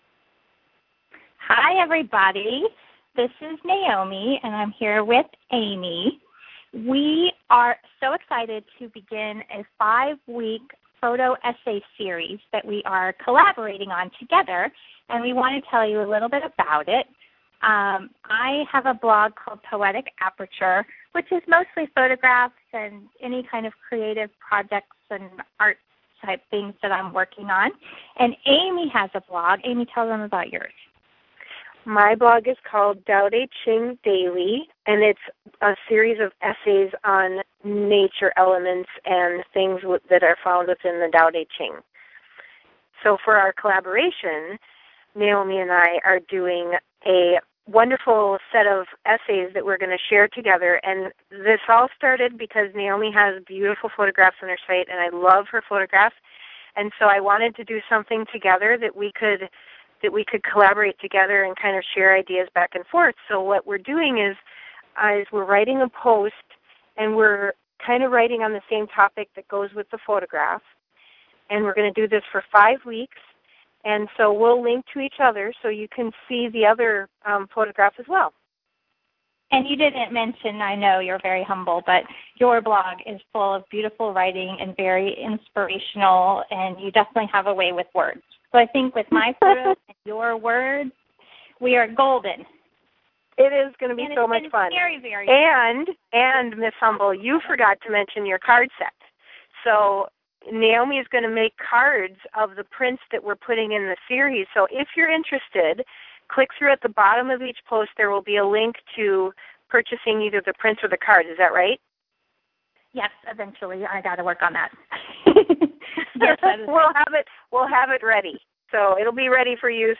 I thought it would be super weird to listen to my voice, but we sound pretty awesome, I think!